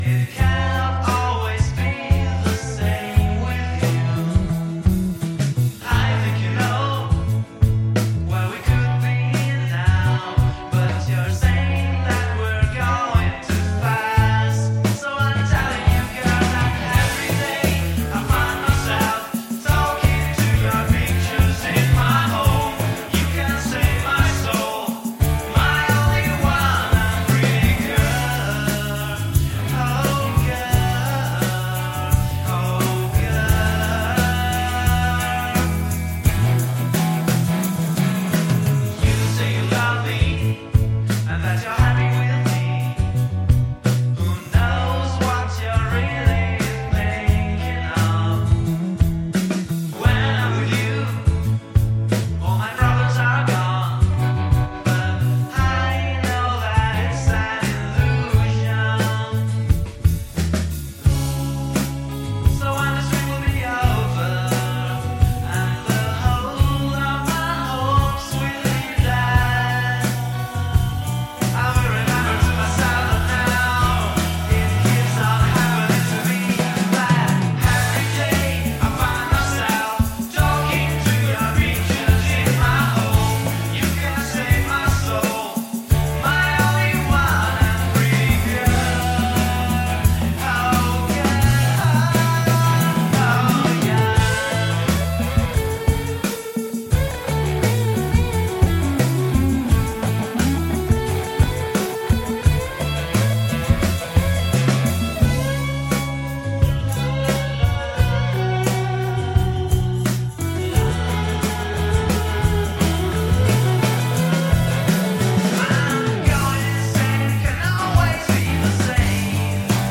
Intervista The Poets | Radio Città Aperta